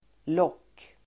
Uttal: [låk:]